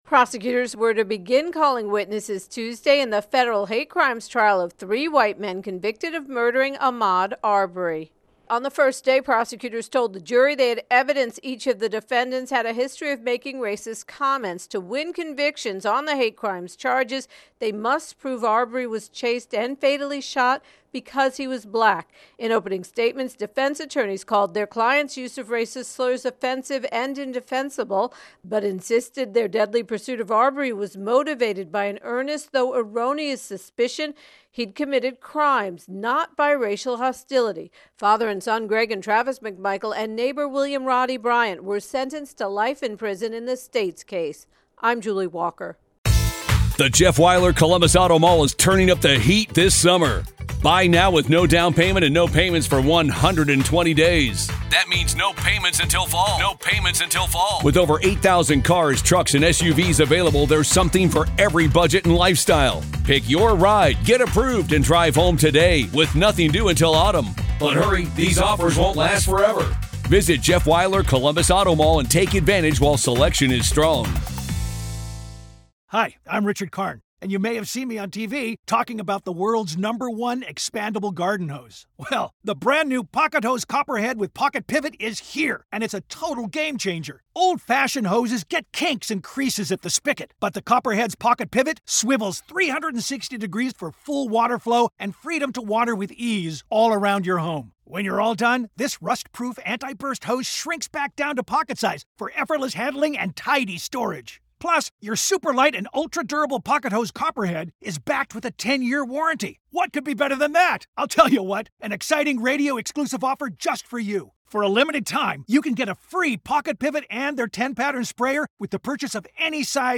Ahmaud Arbery Hate Crimes intro and voicer